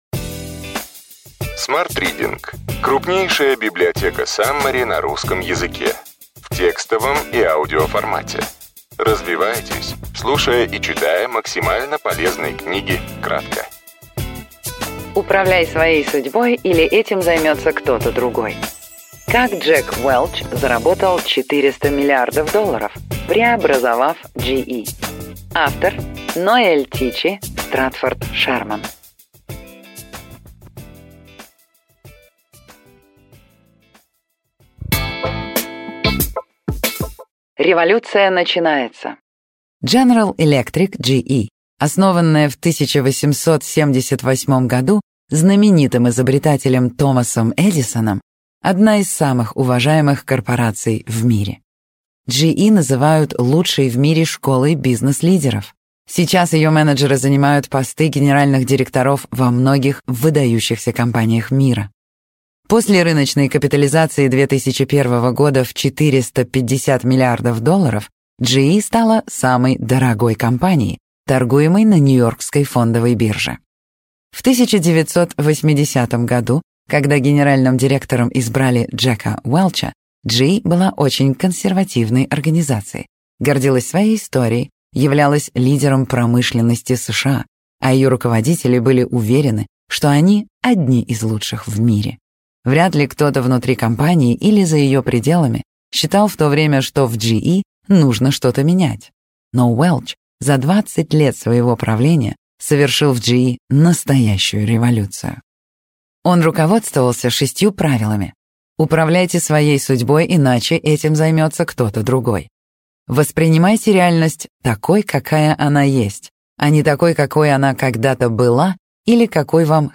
Аудиокнига Ключевые идеи книги: Управляй своей судьбой или этим займется кто-то другой.